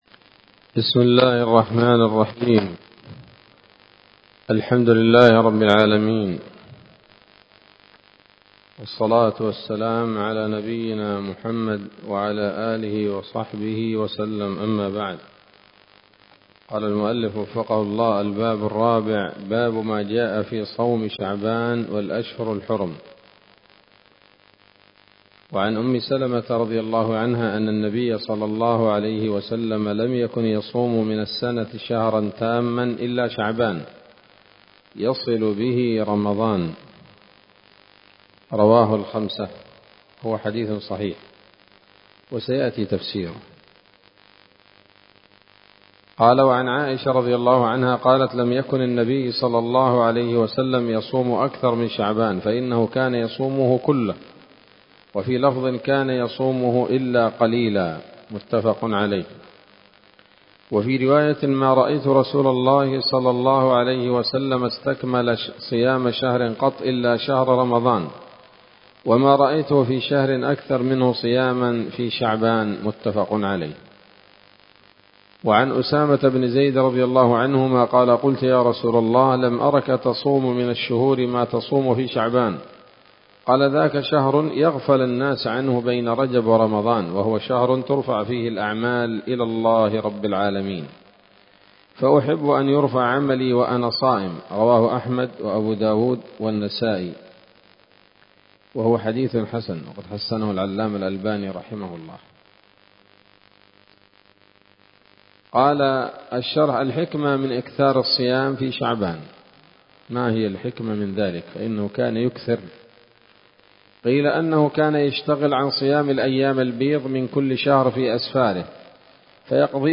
الدرس الثالث والعشرون من كتاب الصيام من نثر الأزهار في ترتيب وتهذيب واختصار نيل الأوطار